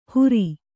♪ huri